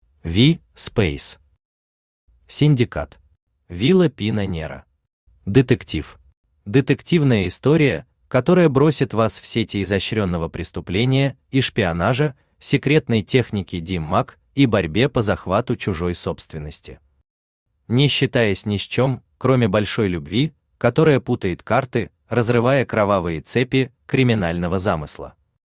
Аудиокнига Синдикат | Библиотека аудиокниг
Aудиокнига Синдикат Автор V. Speys Читает аудиокнигу Авточтец ЛитРес.